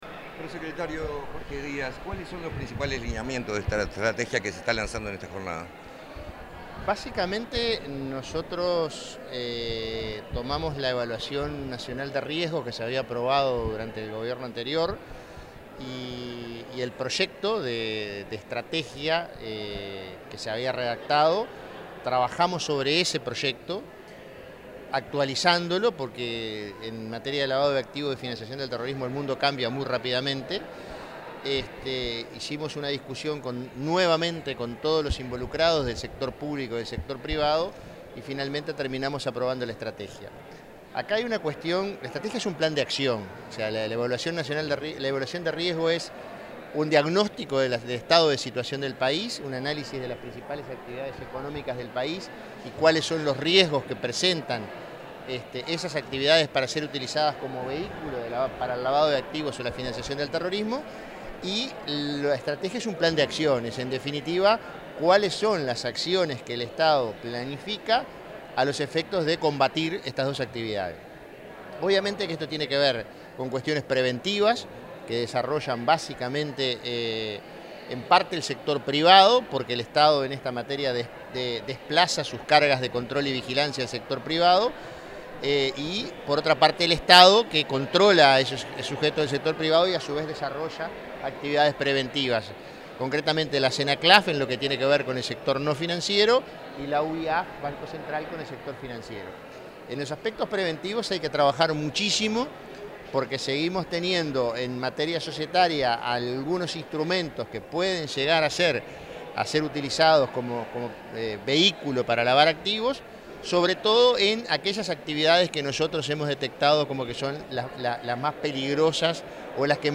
Declaraciones del prosecretario de Presidencia, Jorge Díaz
Declaraciones del prosecretario de Presidencia, Jorge Díaz 07/08/2025 Compartir Facebook X Copiar enlace WhatsApp LinkedIn Tras la presentación de la Estrategia Nacional contra el Lavado de Activos, el Financiamiento del Terrorismo y la Proliferación de Armas de Destrucción Masiva 2025-2030, el prosecretario de la Presidencia, Jorge Díaz, dialogó con la prensa.